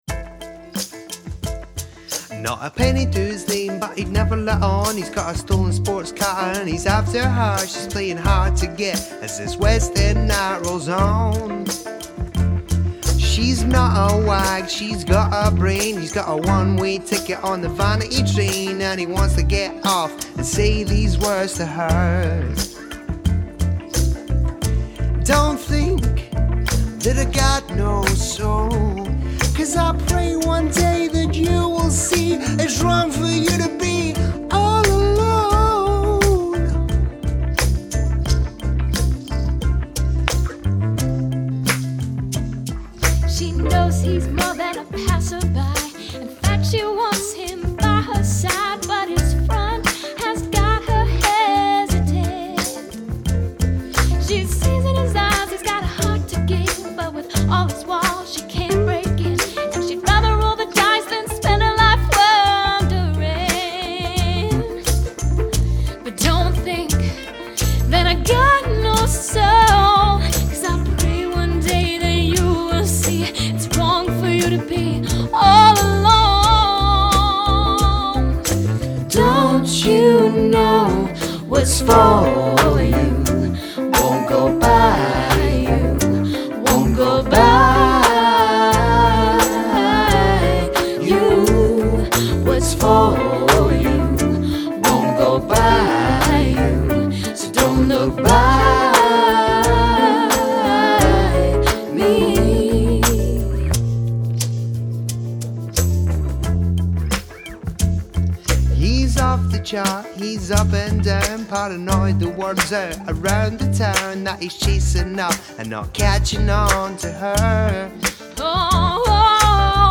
duet
powerful, soulful voice and raw emotion
Acoustic Guitar
Guitar, Sax, Keys
Bass
Percussion